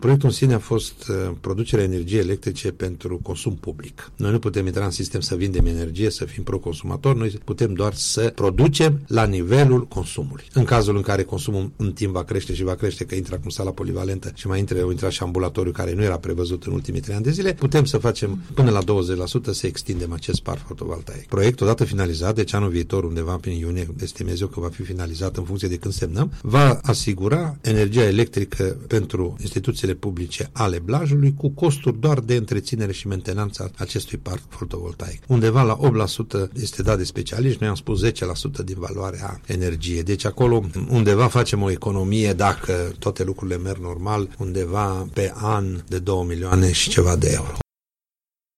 Despre această investiție a vorbit la Unirea FM primarul municipiului Blaj, Gheorghe Valentin Rotar.